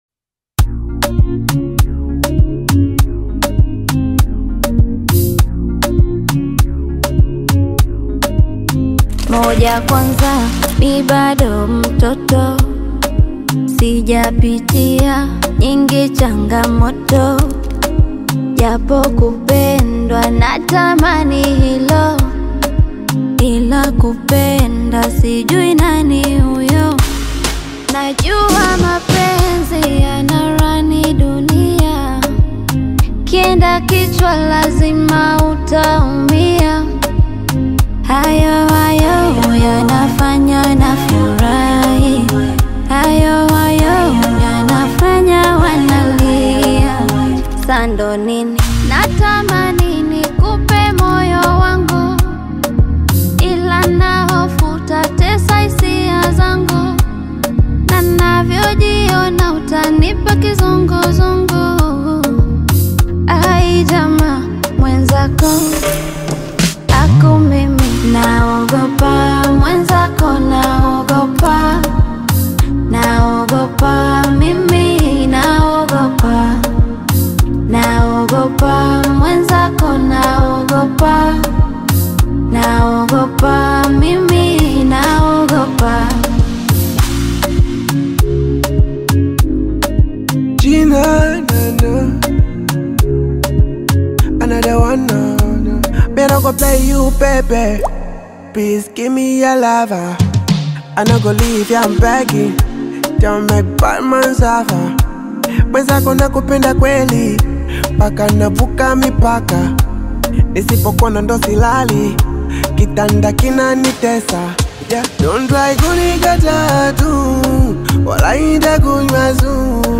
heartfelt Afro-fusion single
With expressive vocal delivery
polished harmonic layers
over smooth production
Genre: Bongo Flava